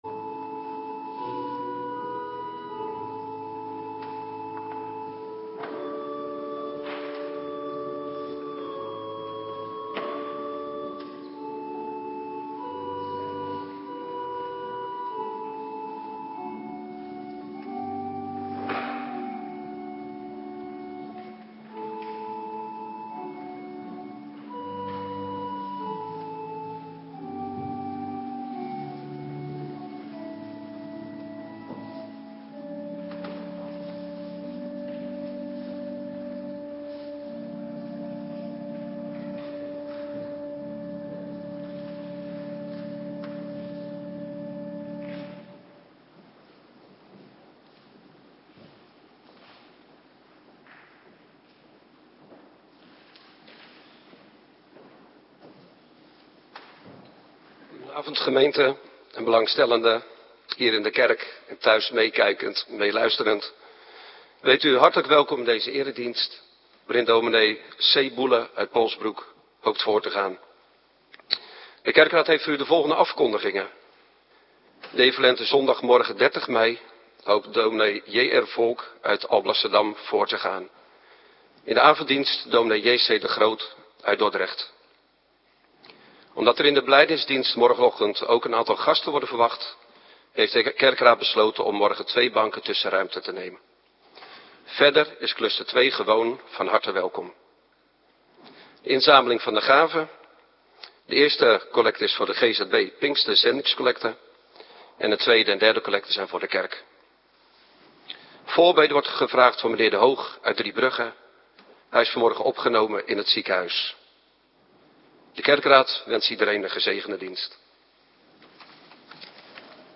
Avonddienst Eerste Pinksterdag - Cluster 1